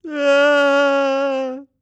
Male_Falling_Shout_05.wav